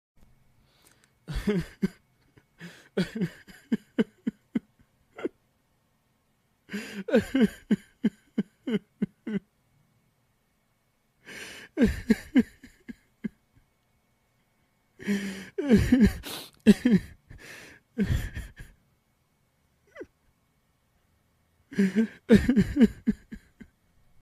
دانلود آهنگ گریه مرد 1 از افکت صوتی انسان و موجودات زنده
دانلود صدای گریه مرد 1 از ساعد نیوز با لینک مستقیم و کیفیت بالا
جلوه های صوتی